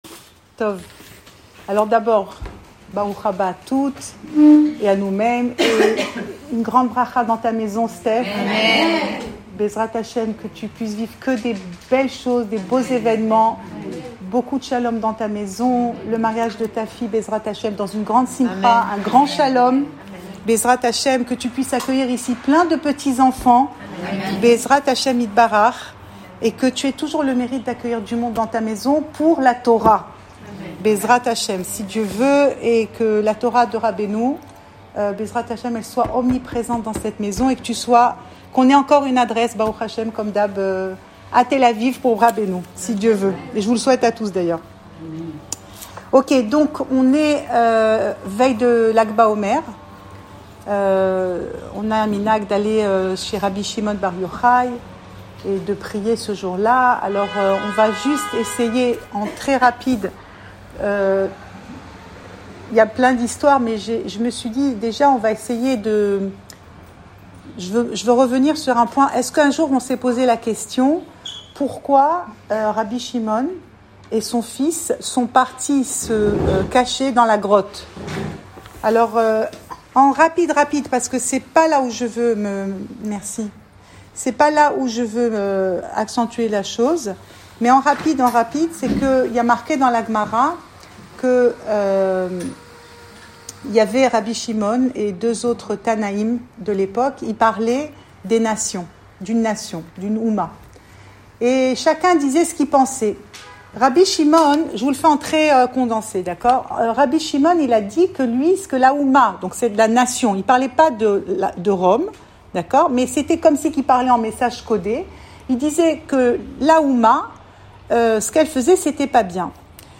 Cours audio Le coin des femmes Pensée Breslev - 28 avril 2021 29 avril 2021 Rabbi Shimon bar Yohaï & Rabbi Nahman : la rencontre. Enregistré à Tel Aviv